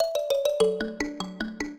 mbira
minuet11-8.wav